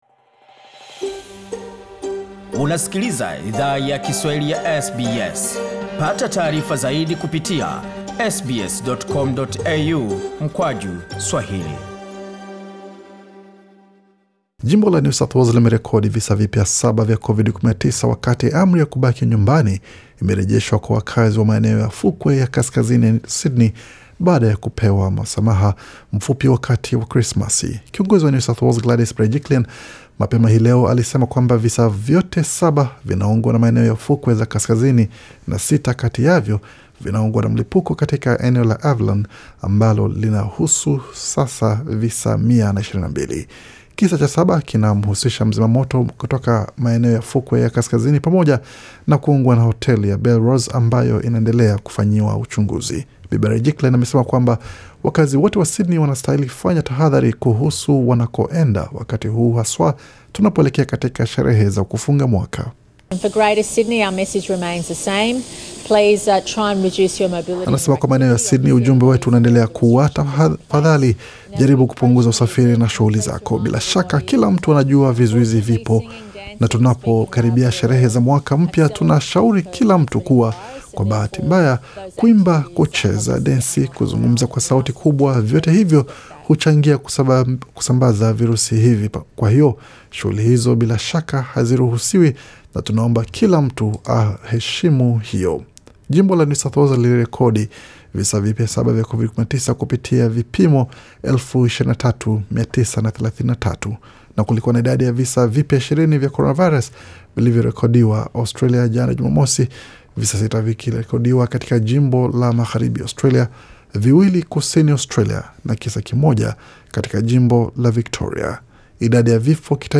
Taarifa ya habari 27 Disemba 2020